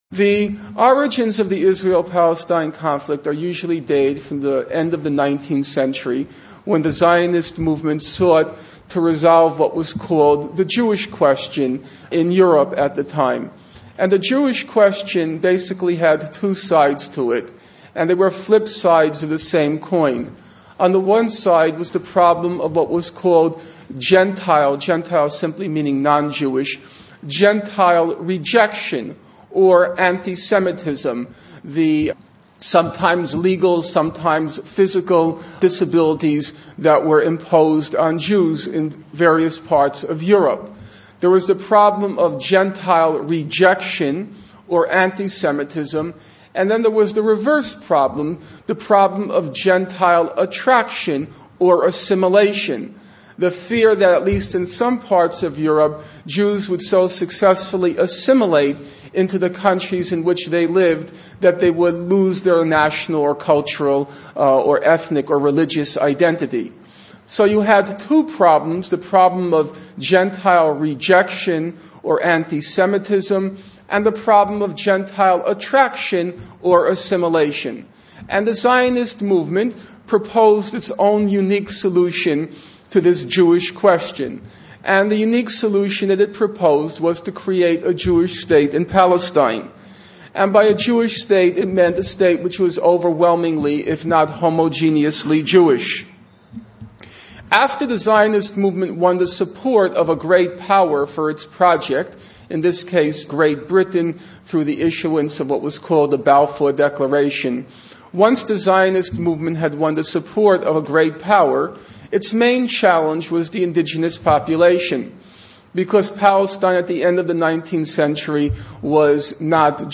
For those relatively new to the conflict, I usually point them towards Ilan Pappe’s The Ethnic Cleansing of Palestine, Finkelstein’s An Introduction to the Israel-Palestine Conflict, Occupation 101 and this lecture, also by Norman Finkelstein, An Issue of Justice. It is one of the best lectures I’ve heard and succinctly covers the main areas of the conflict in an hour.